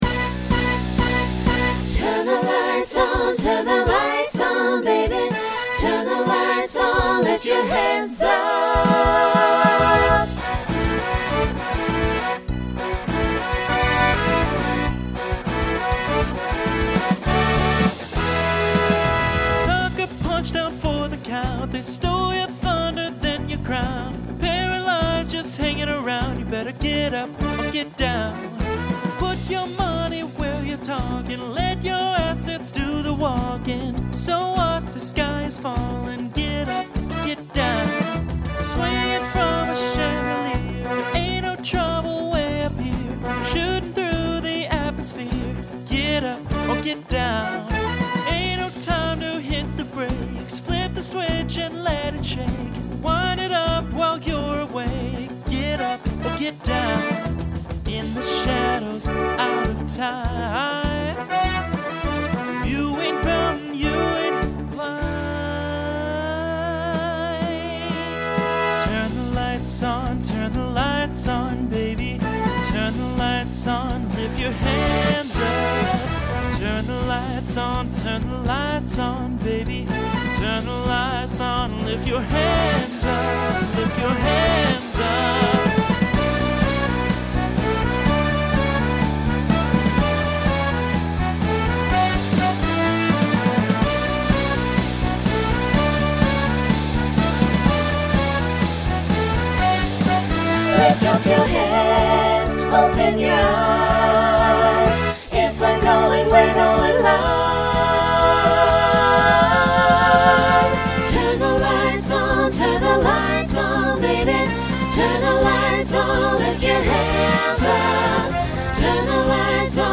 Extended solo to facilitate costume change.
Genre Country , Rock
Transition Voicing SATB